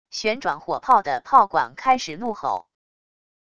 旋转火炮的炮管开始怒吼wav音频